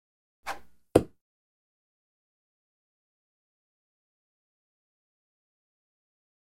Звуки дротиков
Звук летящего дротика в воздухе брошенный дротик